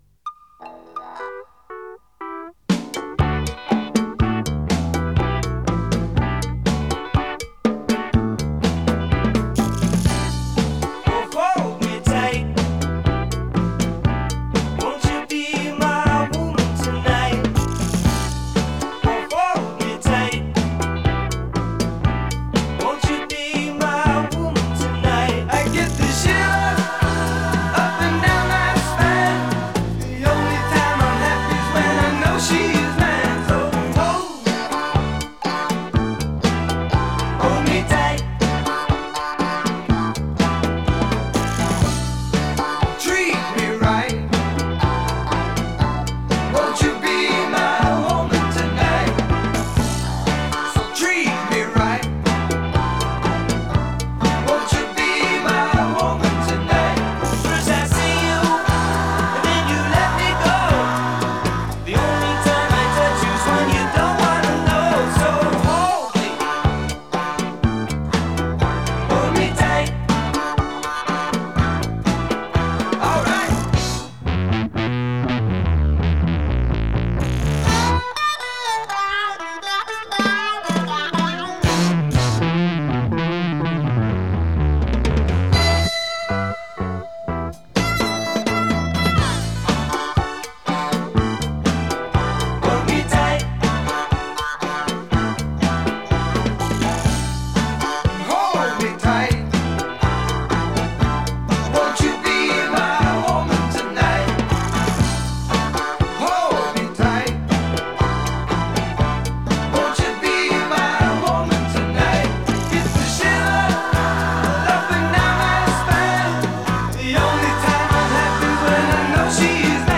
Genre: Folk-Rock.